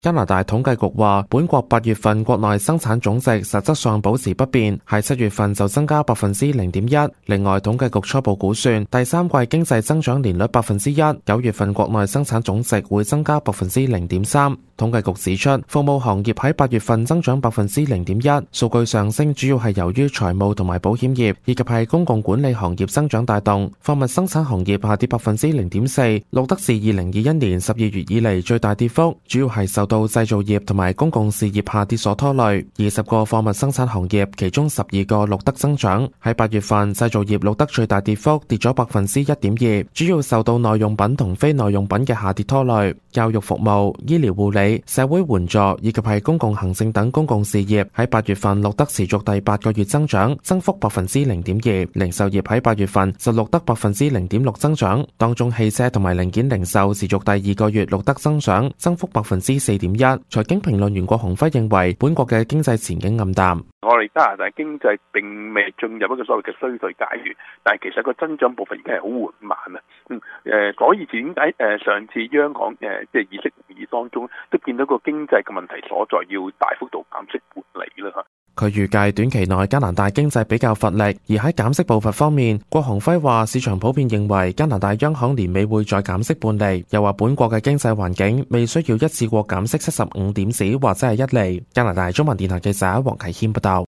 Canada/World News 全國/世界新聞
news_clip_21115.mp3